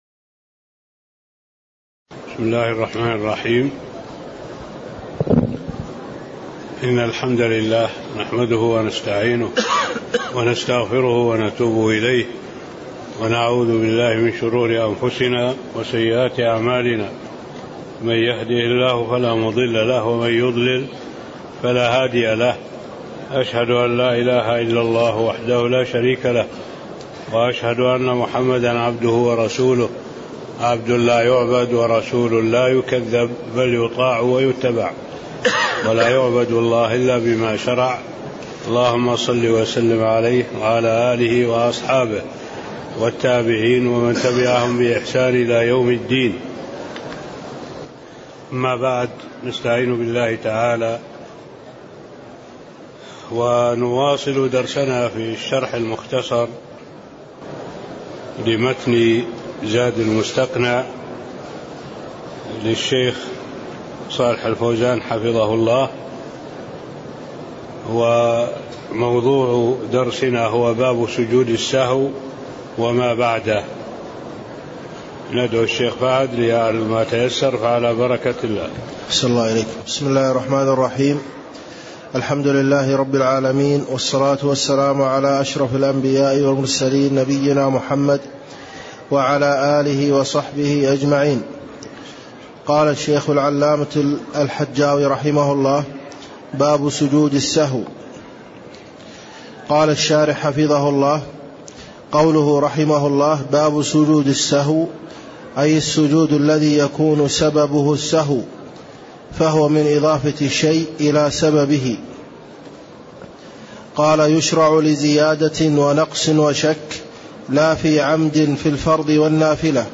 تاريخ النشر ٢٥ جمادى الأولى ١٤٣٤ هـ المكان: المسجد النبوي الشيخ: معالي الشيخ الدكتور صالح بن عبد الله العبود معالي الشيخ الدكتور صالح بن عبد الله العبود فصل سجود السهو (07)